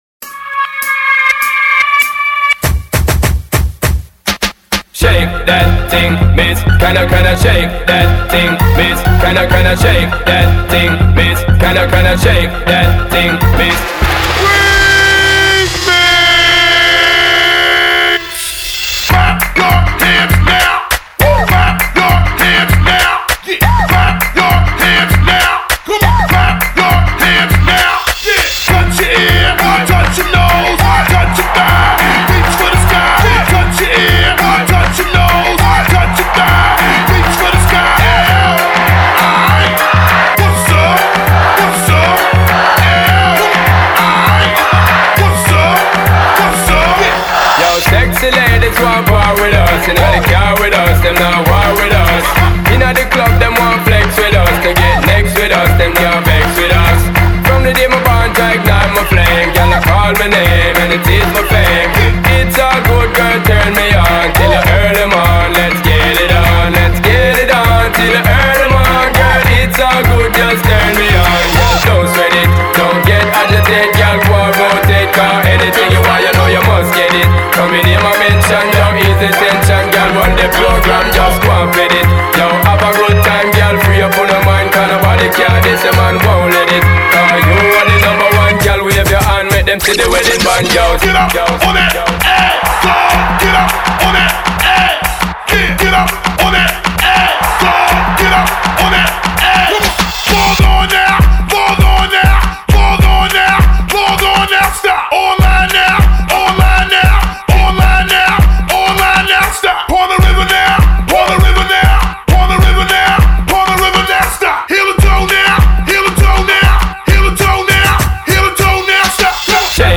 энергичная танцевальная композиция в жанре хип-хоп и EDM